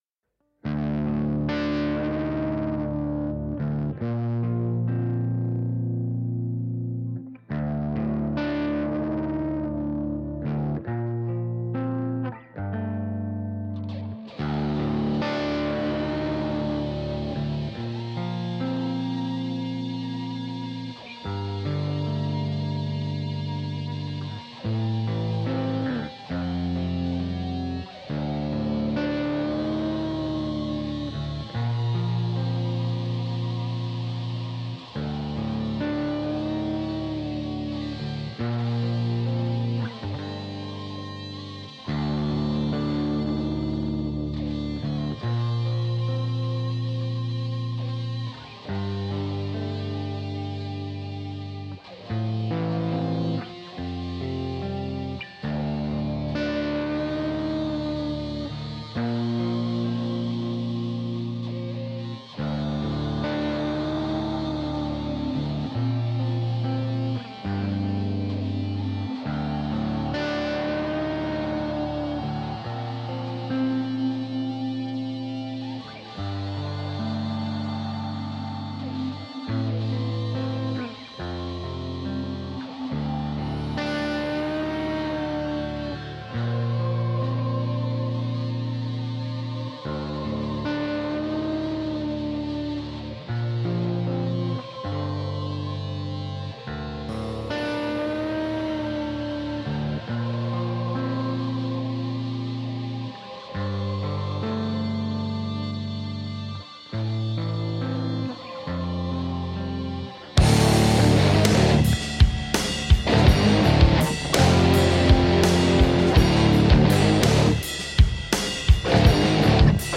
Brooding, melodic grunge.
Tagged as: Hard Rock, Metal, Indie Rock